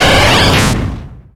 Cri de Pyroli dans Pokémon X et Y.